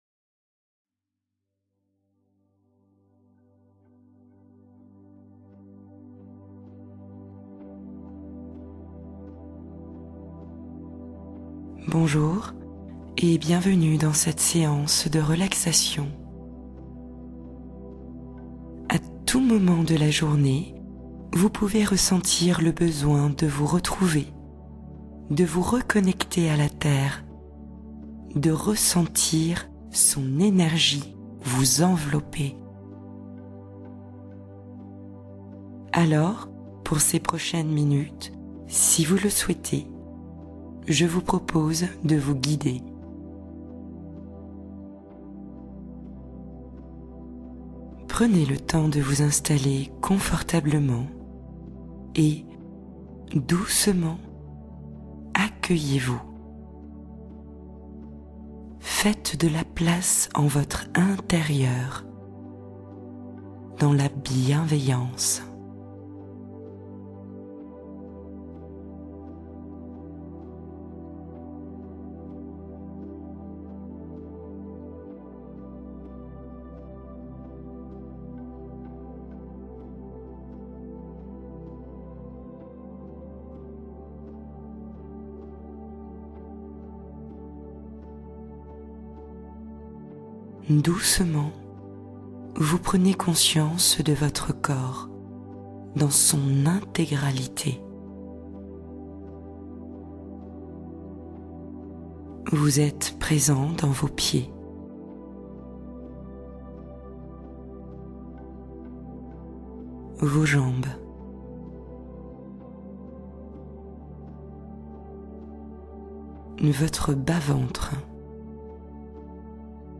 Rencontre intime avec votre véritable essence | Relaxation guidée pour vous retrouver